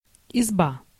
An izba (Russian: изба́, IPA: [ɪzˈba]
Ru-изба.ogg.mp3